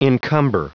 Prononciation du mot encumber en anglais (fichier audio)
Prononciation du mot : encumber
encumber.wav